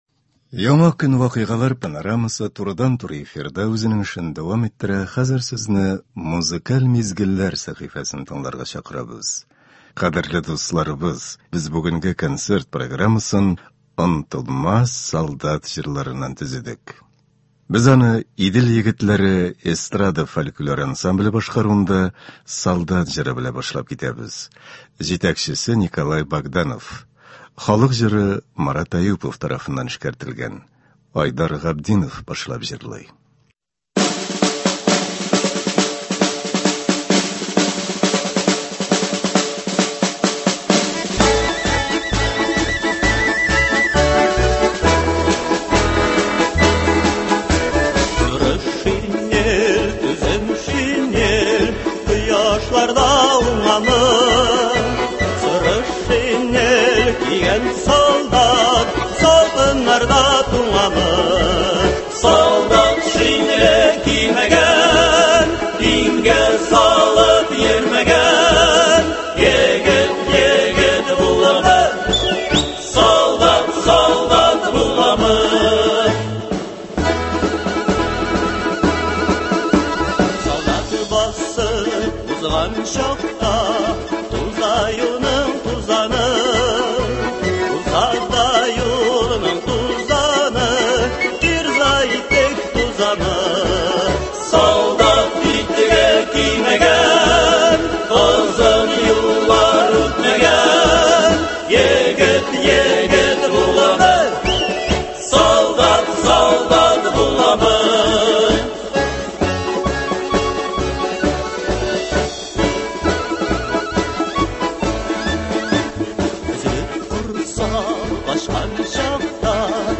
Ватанны саклаучылар бәйрәменә мөнәсәбәтле ял көнен без “Солдат җырлары” дигән концерт белән башлап җибәрәбез.